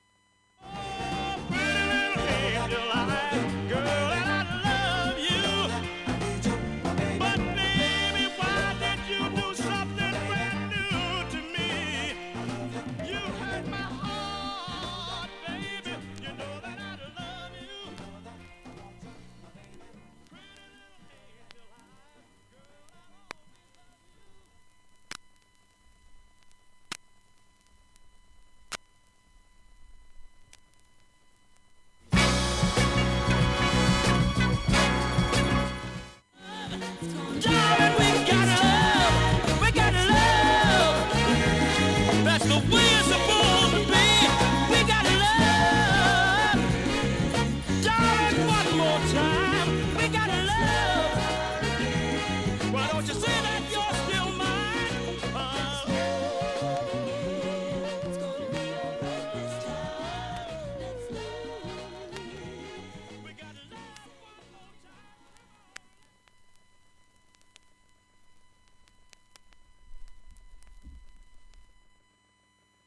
フェイドアウト部に小さいプツ出ます。
ごくわずかのプツ６回出ます。
現物の試聴（上記）できます。音質目安にどうぞ